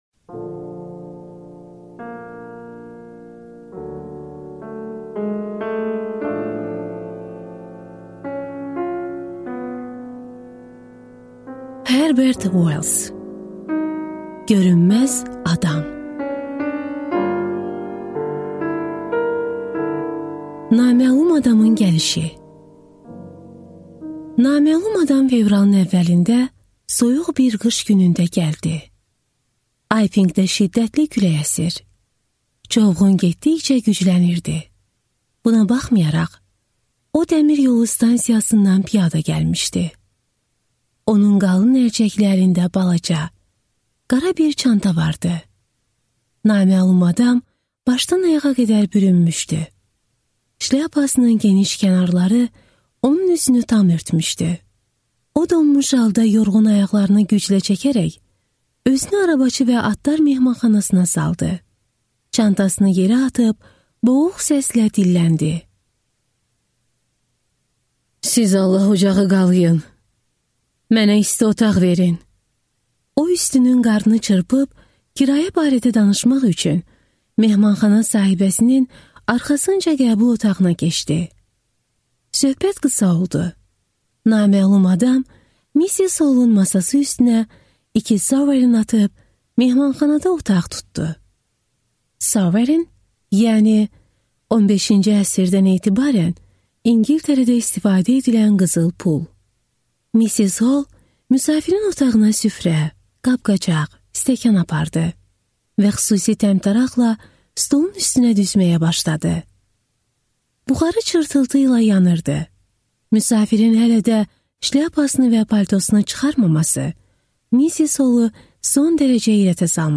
Аудиокнига Görünməz adam | Библиотека аудиокниг